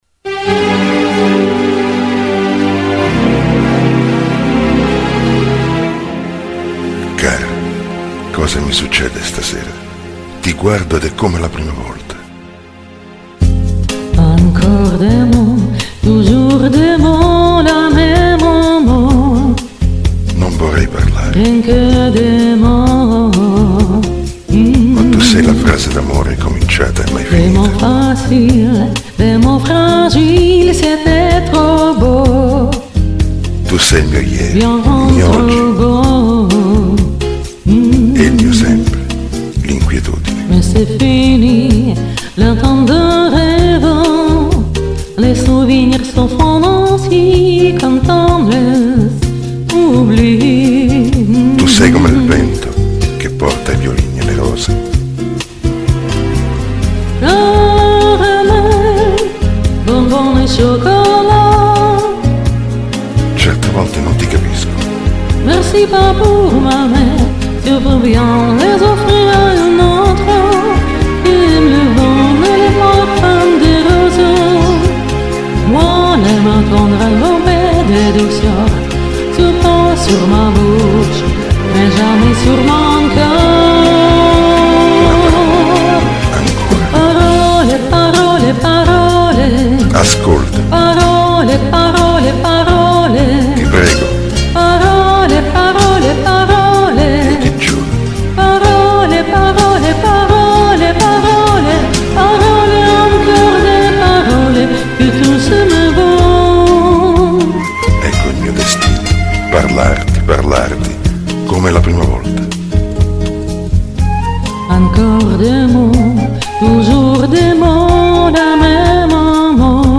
Зато ты не одна, а с партнером - там ведь сам Делон у тебя?
Вчера Вы совсем тихо звучали, мужик Вас реально забивал.